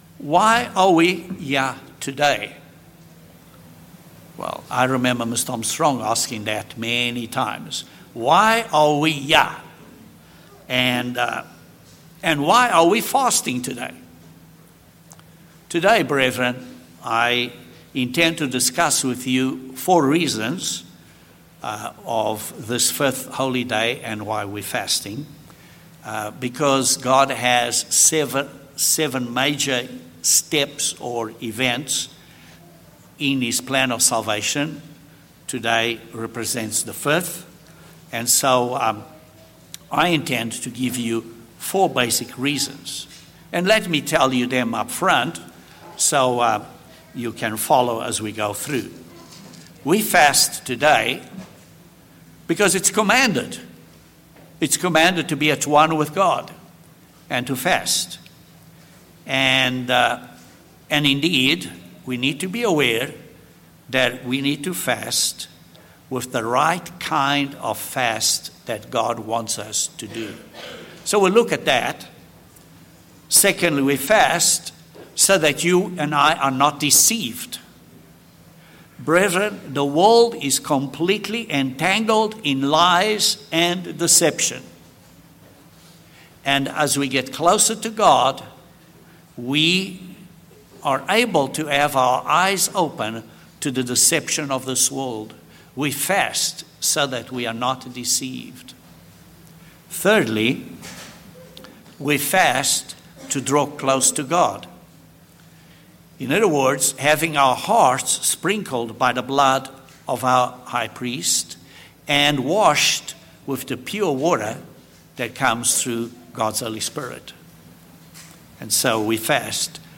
This sermon addresses four reasons why we are to fast today to be AT ONE with God.